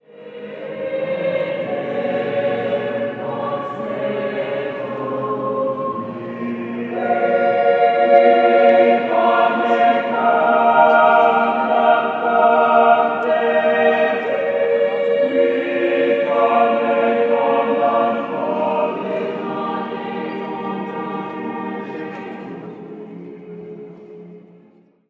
Category: Christian Ringtones